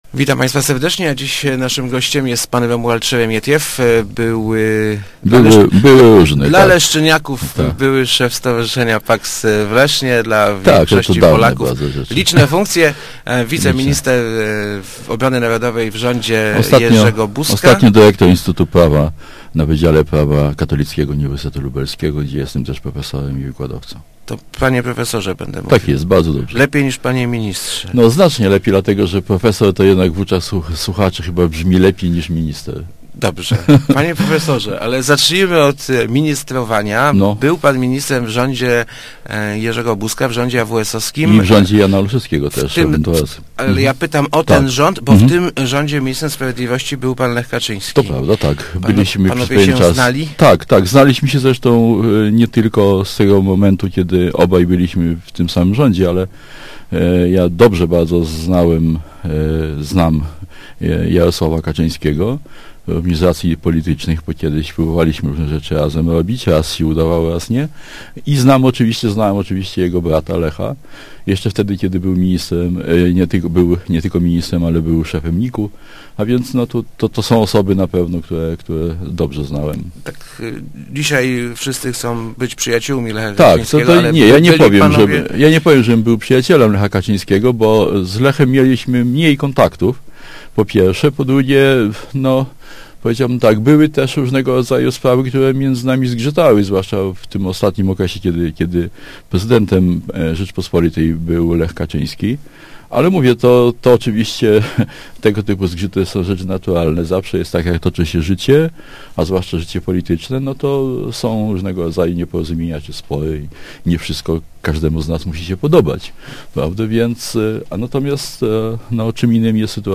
Nie podejrzewa�em premiera Putina o zdolno�� do wzrusze� - mówi� w Rozmowach Elki Romulad Szeremietiew, by�u wiceminister Obrony Narodowej w rz�dzie AWS.